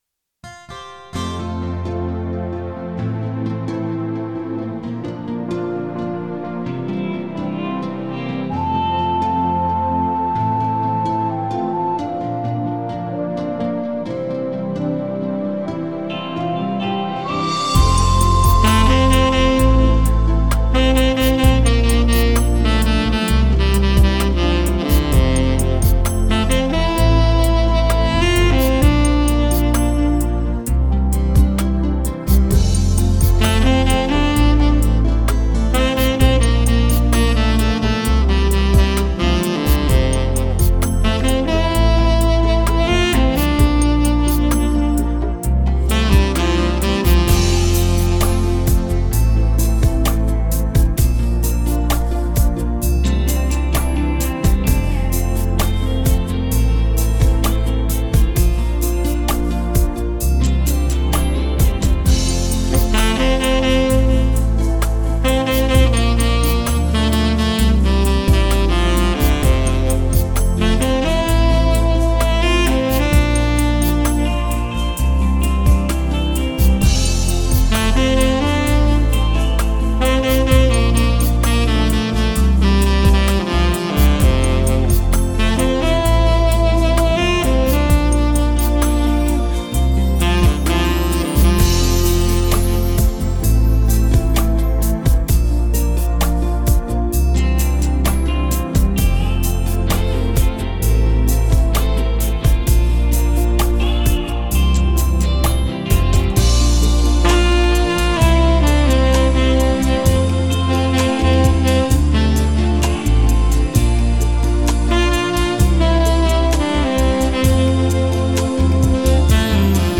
Жанр: Downtempo, Chillout, Lounge